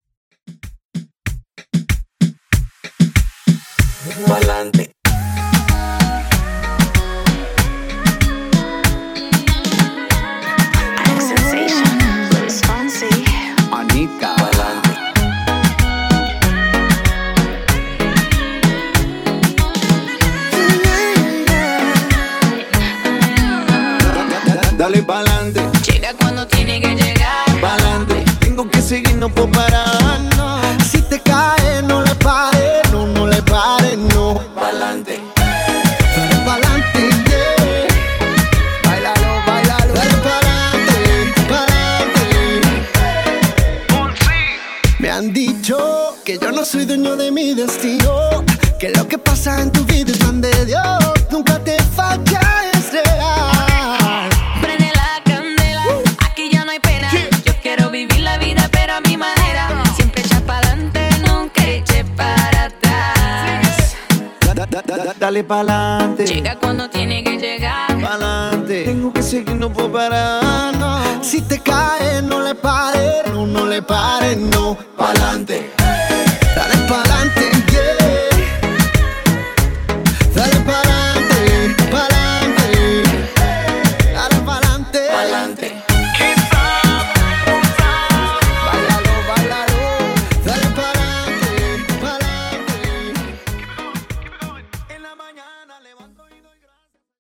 Reggaeton)Date Added